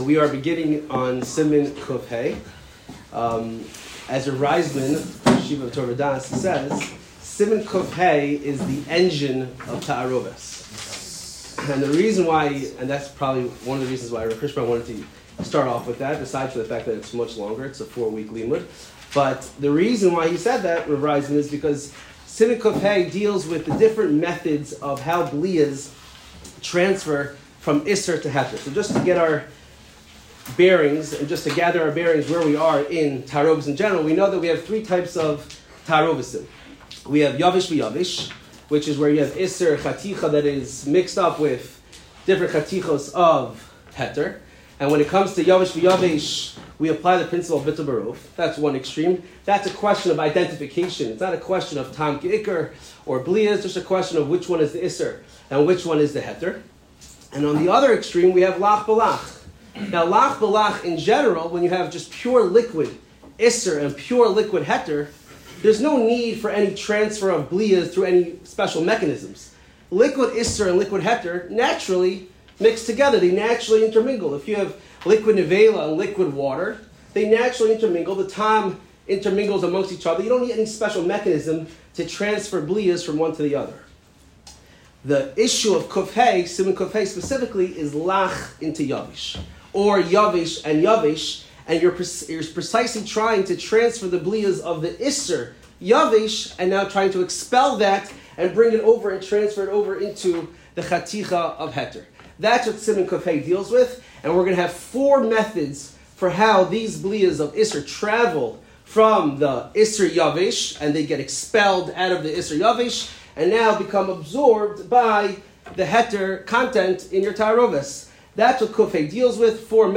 Shiur 16 - Kavush